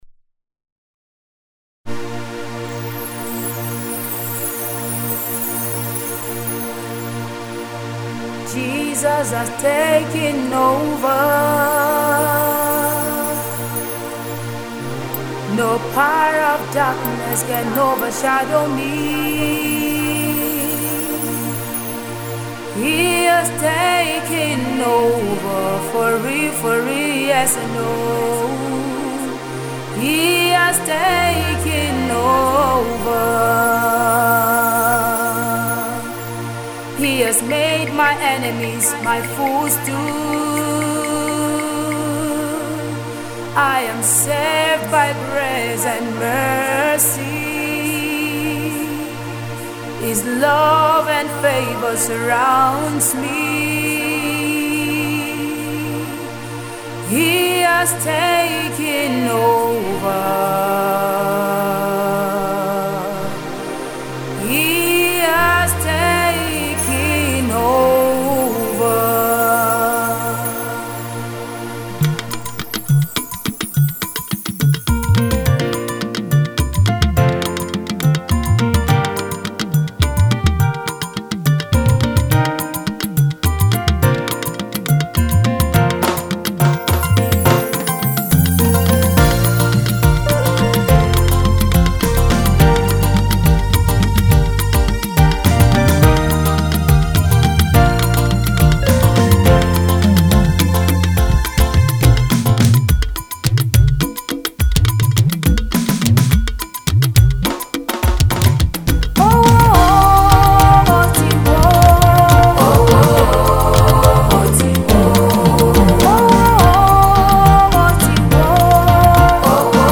Nigeria Gospel Music